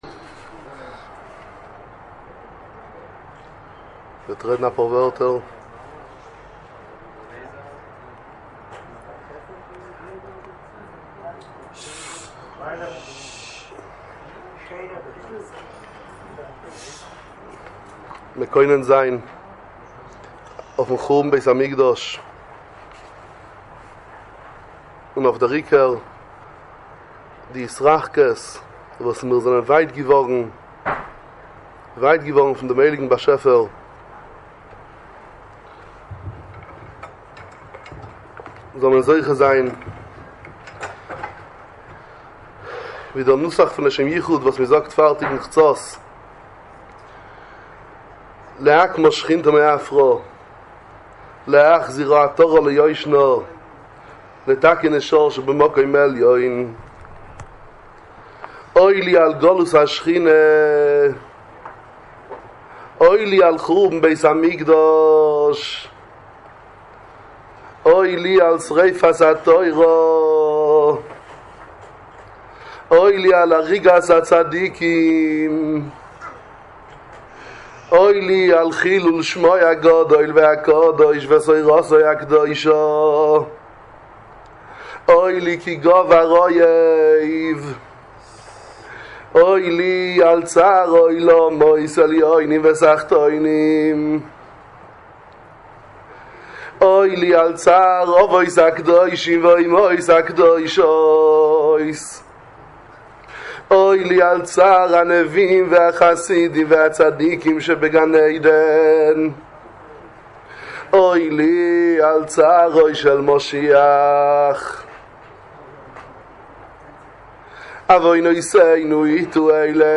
ליל_תשעה_באב_בישיבת_אורייתא_2018.mp3